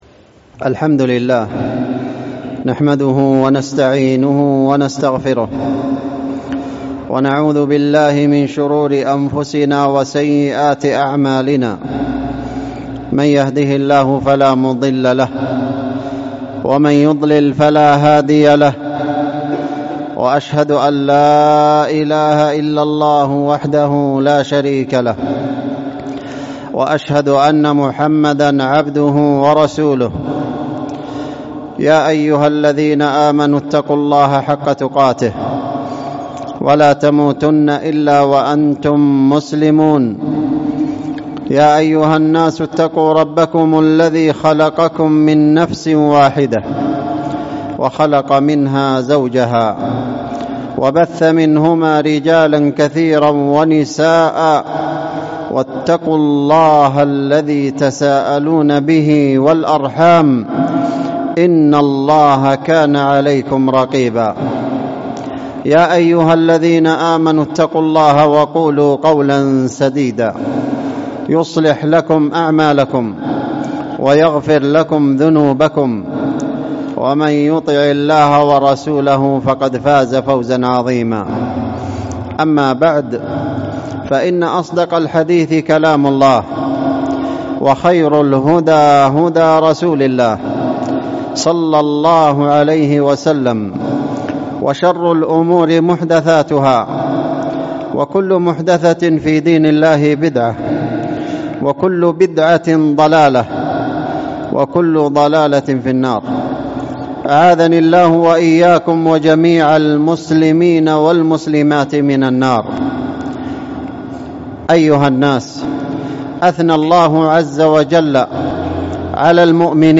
وما بدلوا تبديلا | الخطب والمحاضرات
ألقيت الخطبة بتاريخ 20 / رجب / 1447